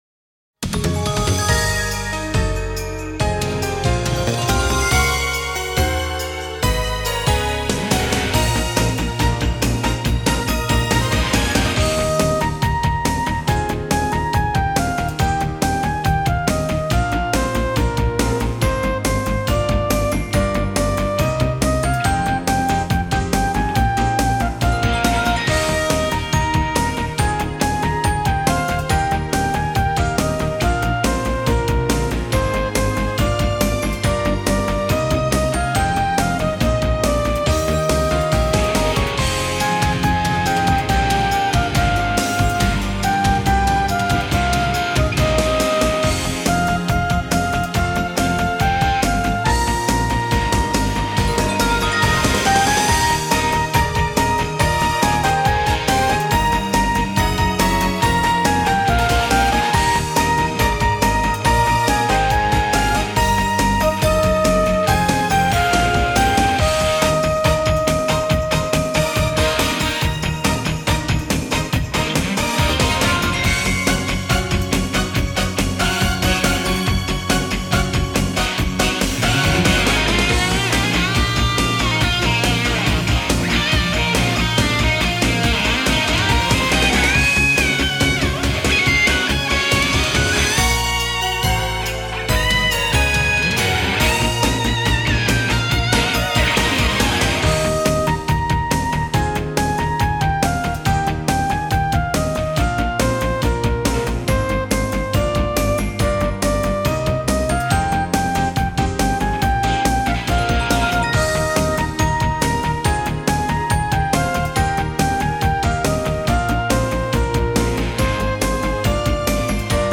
Instrumental  - Караоке-версия Opening 3-го сезона.